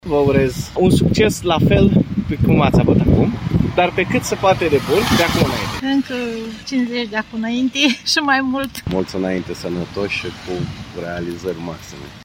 Caravana Europa FM ajunge în orașul tău pentru a sărbători împreună 25 de ani de când suntem pe aceeași frecvență. Astăzi a oprit în Piatra Neamț
Unii ascultători au dorit să transmită și câteva urări cu ocazia împlinirii a 25 de ani de activitate…